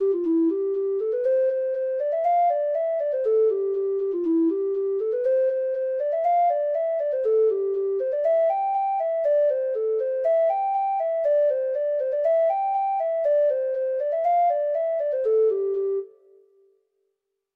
Finnegan's Wake (Irish Folk Song) (Ireland)
Free Sheet music for Treble Clef Instrument
Traditional Music of unknown author.